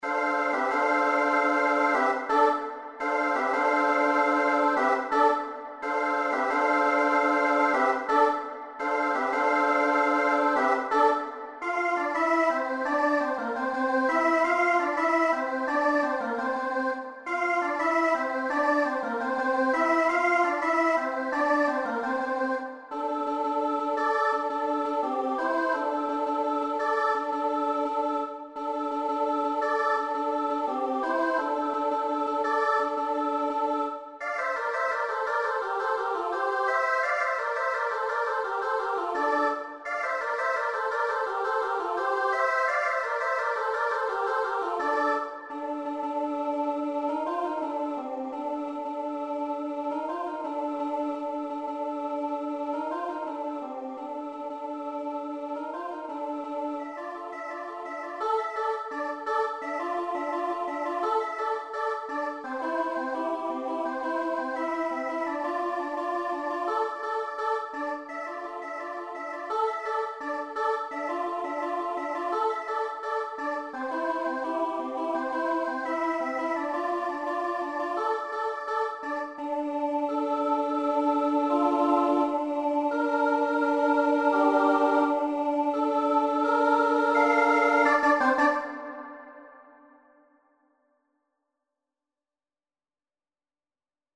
An energetic piece for SSAA with body percussion
SSAA with body percussion